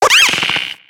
Cri de Zébibron dans Pokémon X et Y.